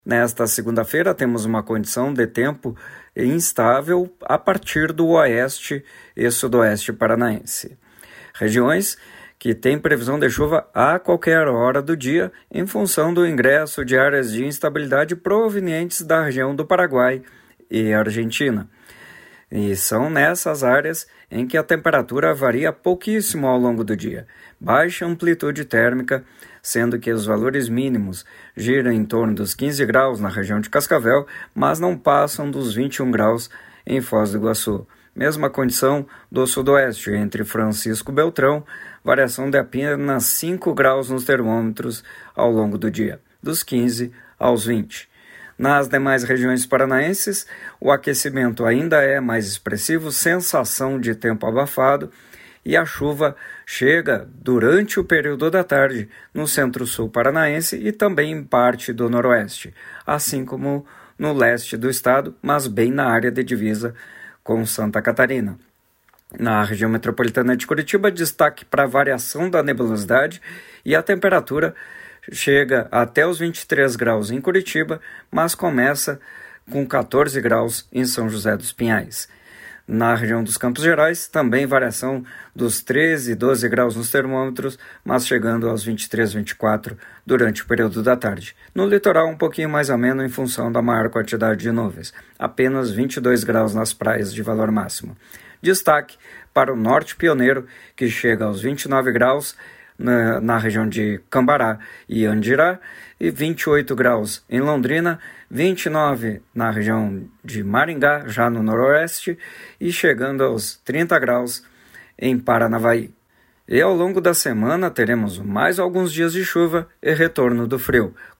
Previsão
Ouça a previsão em detalhes para o restante do estado com o meteorologista